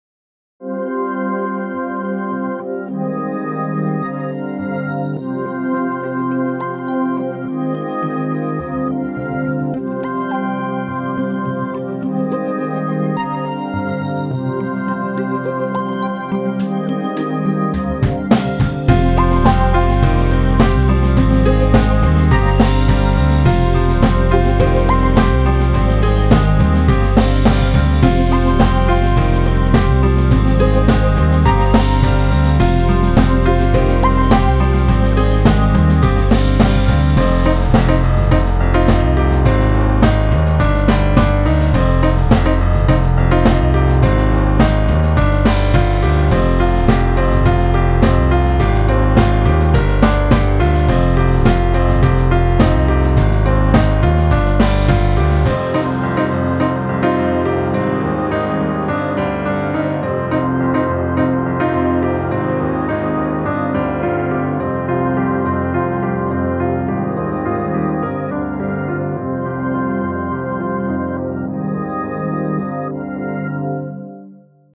Home > Music > Rock > Bright > Medium > Running